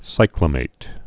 (sīklə-māt, sĭklə-)